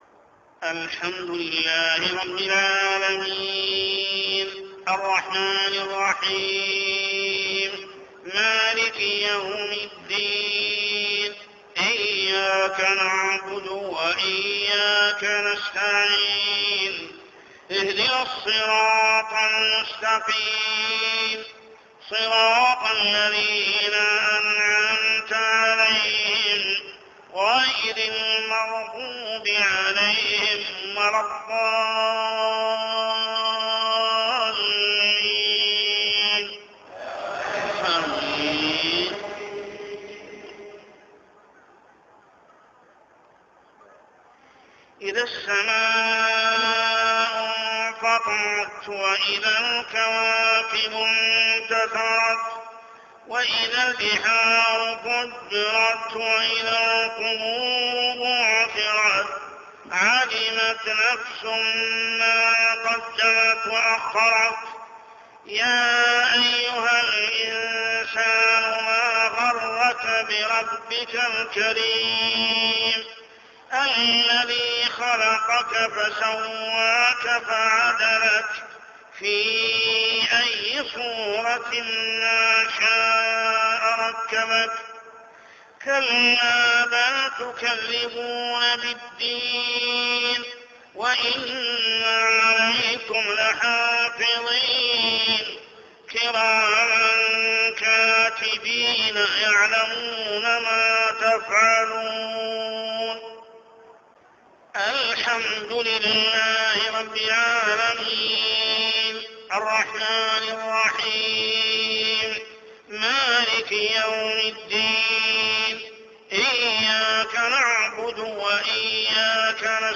عشائيات عام 1425هـ سورة الإنفطار كاملة | Isha prayer Surah Surah Al-Infitar > 1425 🕋 > الفروض - تلاوات الحرمين